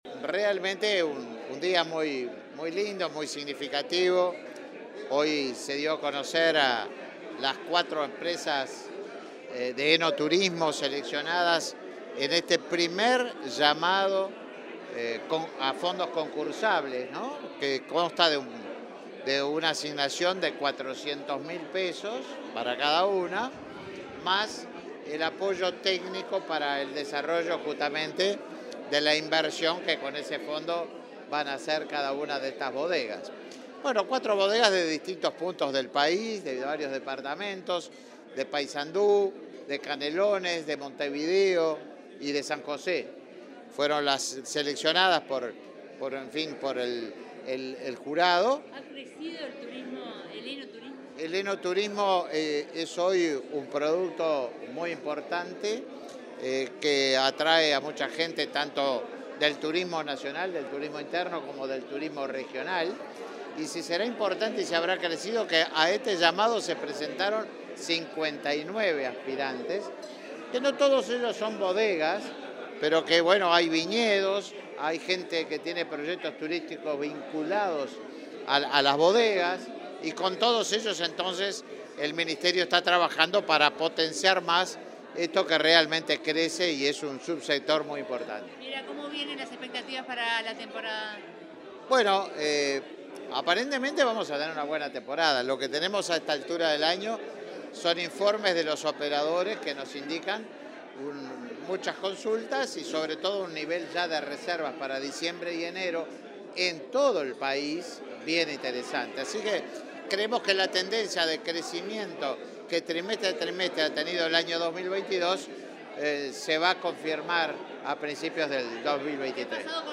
Declaraciones a la prensa del ministro de Turismo, Tabaré Viera
Declaraciones a la prensa del ministro de Turismo, Tabaré Viera 21/12/2022 Compartir Facebook X Copiar enlace WhatsApp LinkedIn Este miércoles 21, el director nacional de Turismo, Roque Baudean, y el ministro Tabaré Viera participaron en el acto de premiación de los seleccionados en el fondo concursable para desarrollar oferta enoturística. Luego el secretario de Estado dialogó con la prensa.